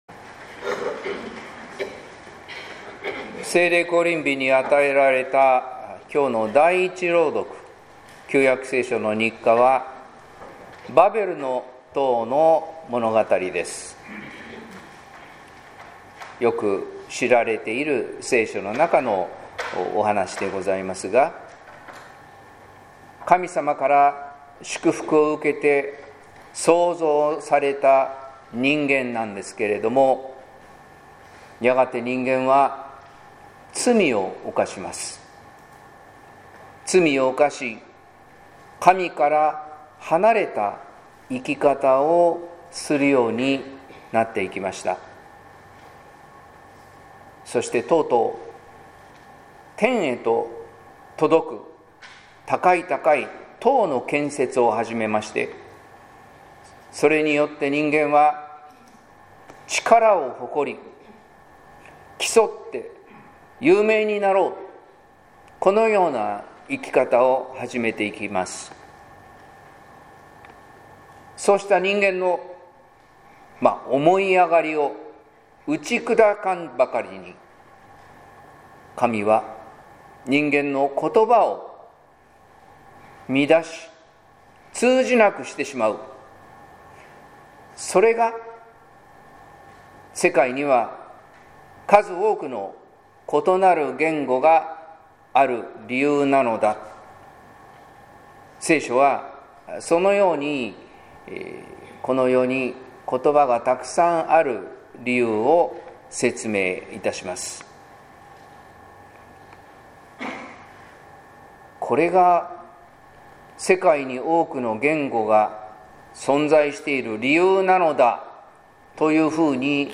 説教「混乱を正す聖霊」（音声版） | 日本福音ルーテル市ヶ谷教会